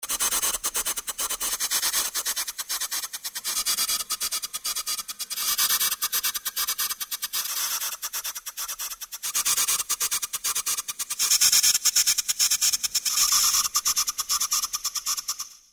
Efecto de reloj rítmico
efecto
reloj
rítmico